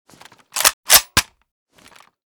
l85_unjam.ogg